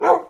mob / wolf / bark2.ogg
bark2.ogg